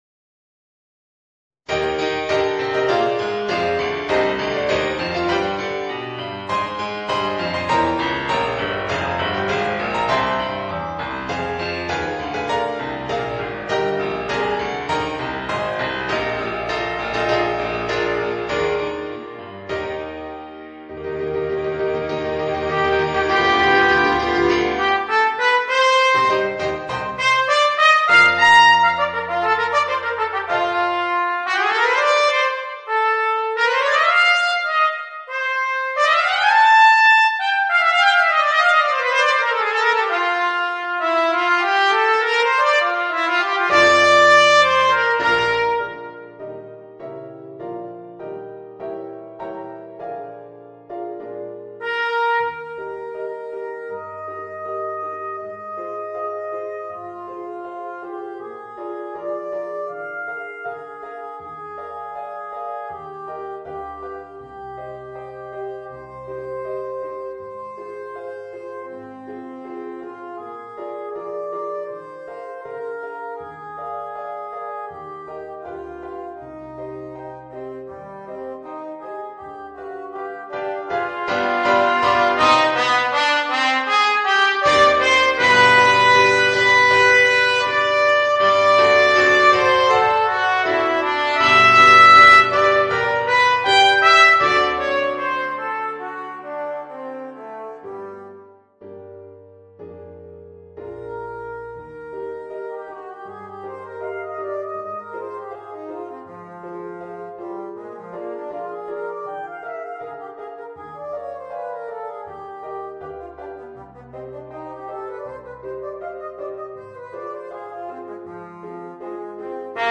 Voicing: Cornet and Piano